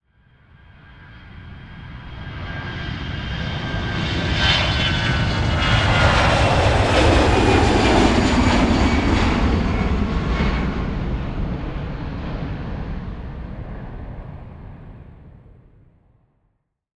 48 kHz 24 bit Stereo
Title: Airplane Lift-Off The sound of an aircraft lifting off at the end of the runway Keywords: liftoff, depart, departing, airport
airplane-lift-off-01.wav